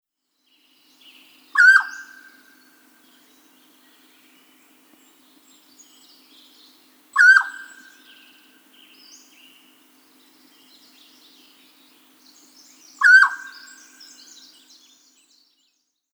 Калифорнийский горный перепел издает характерные звуки в лесу